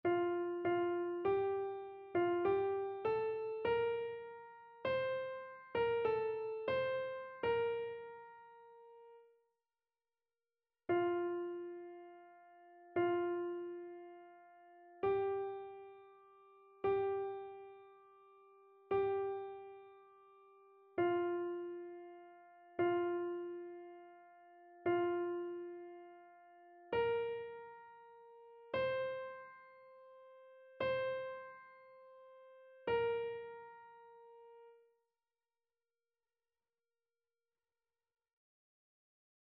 Chœur
annee-c-temps-ordinaire-14e-dimanche-psaume-65-soprano.mp3